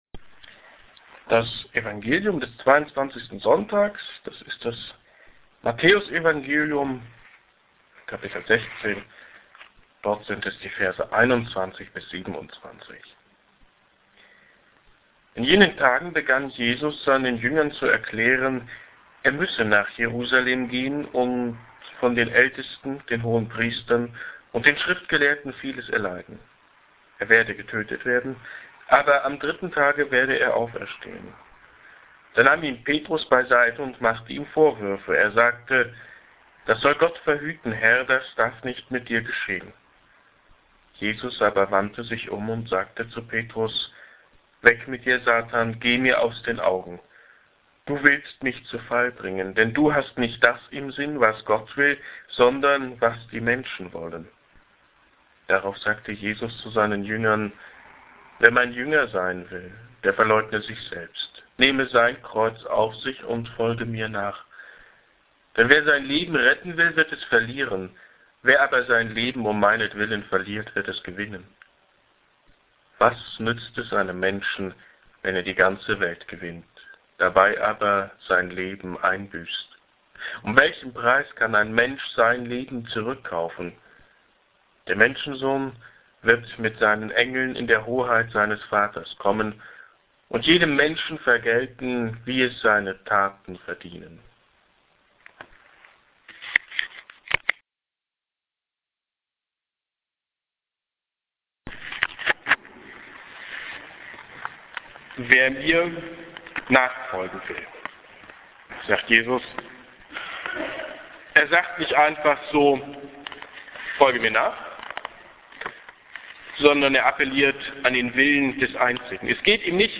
Wer will IHM nachfolgen? Predigt z. 22. So. i. Jkr. Lj. A
Hier auf sehr kurzem Weg die Predigt vom gestrigen Sonntag.